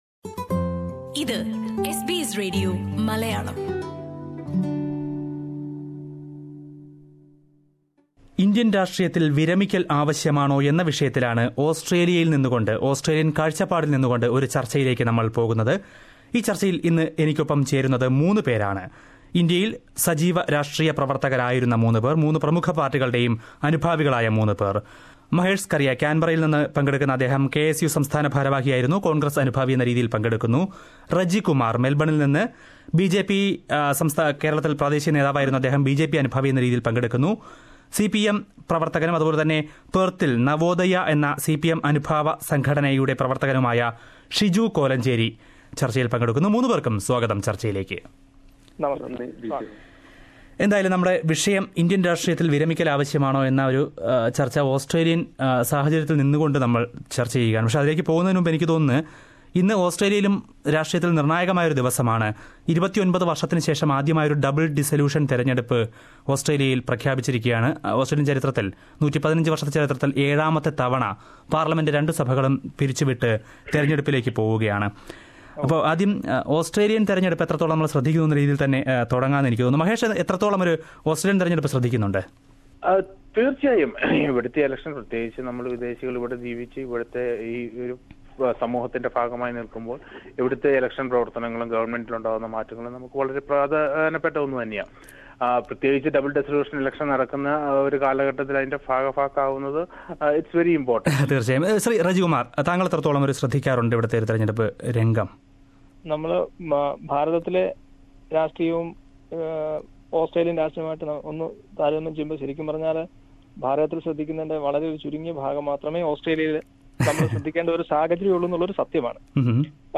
When Kerala is going to polls in a few days, SBS Malayalam Radio holds a discussion.